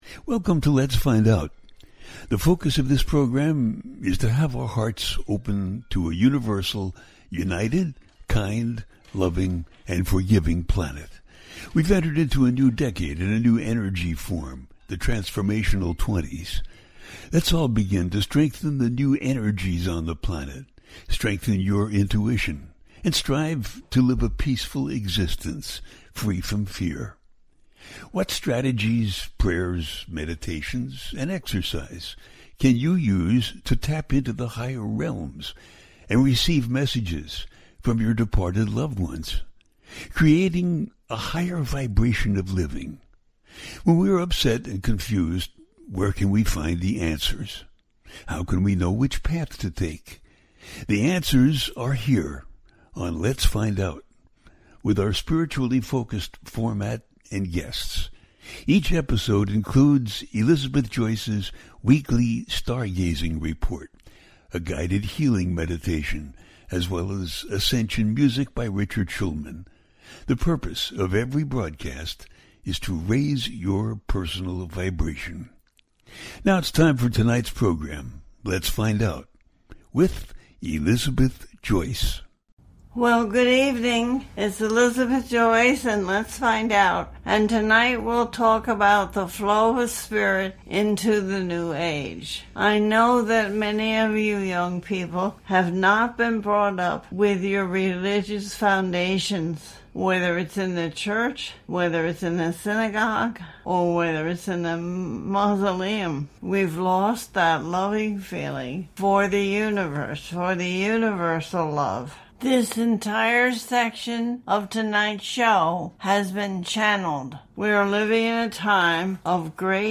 The Flow of Spirit in the Coming New Age - A teaching show